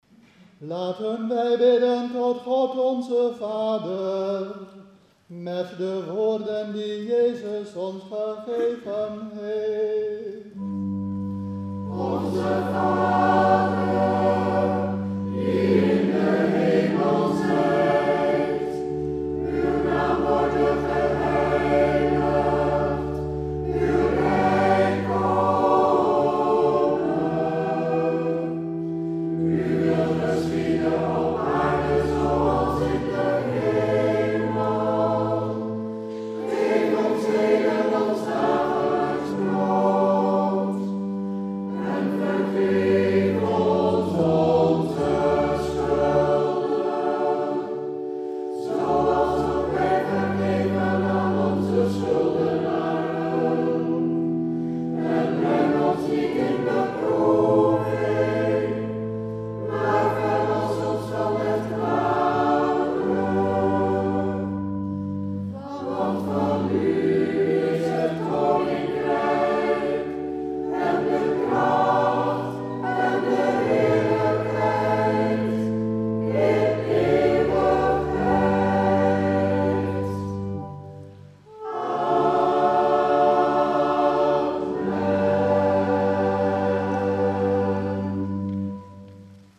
Pinksteren 2017